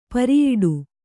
♪ pariyiḍu